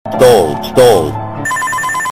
Evil Scary Die Of Death Botón de Sonido